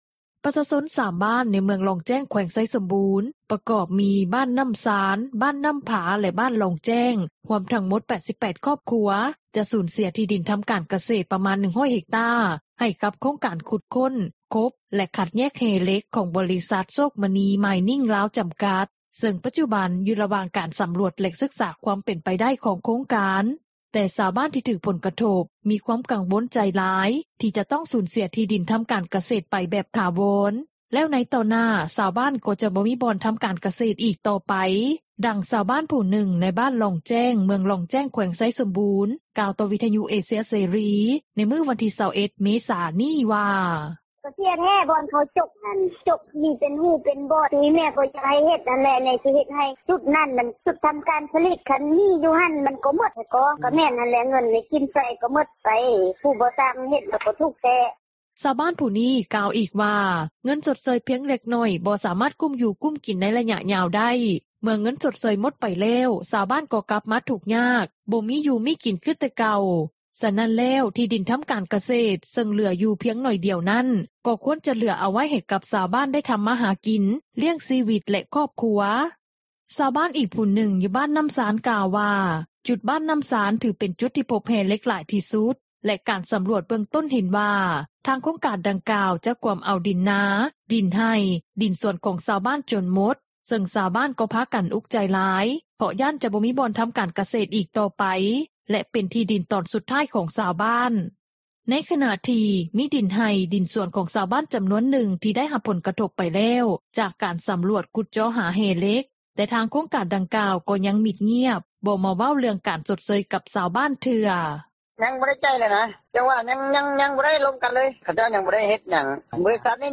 ດັ່ງຊາວບ້ານຜູ້ນຶ່ງ ໃນບ້ານລ່ອງແຈ້ງ ເມືອງລ່ອງແຈ້ງ ແຂວງໄຊສົມບູນ ກ່າວຕໍ່ວິທຍຸເອເຊັຽເສຣີ ໃນມື້ວັນທີ່ 21 ເມສາ ນີ້ວ່າ:
ດັ່ງເຈົ້າໜ້າທີ່ ຜແນກພະລັງງານ ແລະບໍ່ແຮ່ ແຂວງໄຊສົມບູນ ທ່ານນຶ່ງ ກ່າວຕໍ່ວິທຍຸເອເຊັຽເສຣີ ໃນມື້ວັນທີ່ 21 ເມສາ ນີ້ວ່າ:
ດັ່ງນັກອະນຸຮັກສິ່ງແວດລ້ອມ ຊາວລາວທ່ານນຶ່ງ ໃນແຂວງຫຼວງພຣະບາງ ກ່າວຕໍ່ວິທຍຸເອເຊັຽເສຣີ ໃນມື້ວັນທີ່ 21 ເມສາ ນີ້ວ່າ: